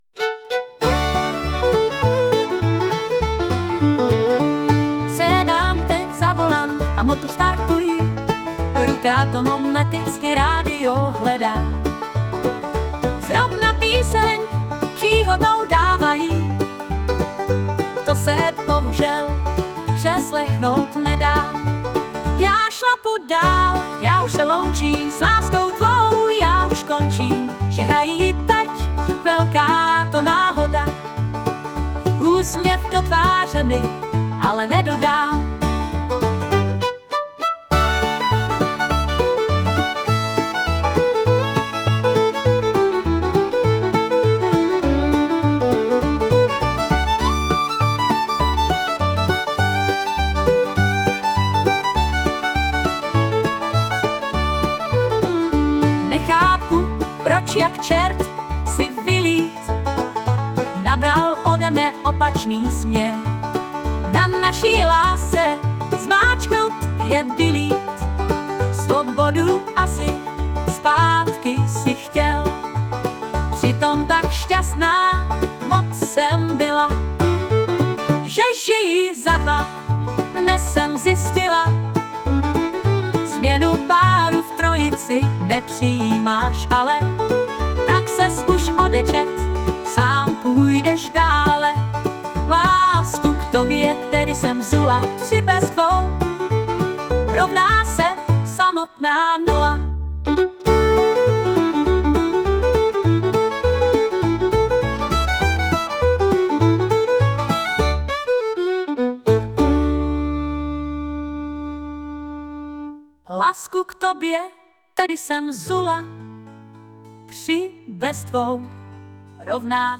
Smutné
* hudba, zpěv: AI